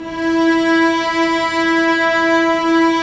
Index of /90_sSampleCDs/Roland - String Master Series/STR_Vcs Bow FX/STR_Vcs Sordino